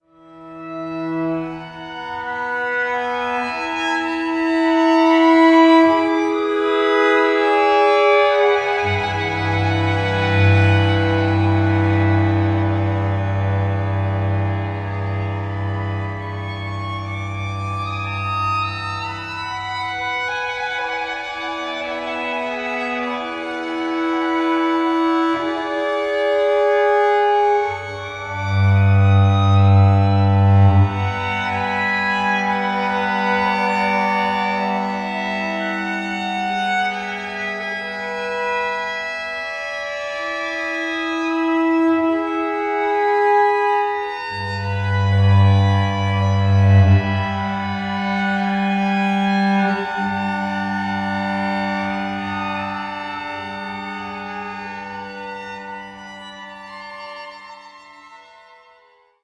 violin
viola
cello
Each musician is a soloist, playing throughout.
Time floats.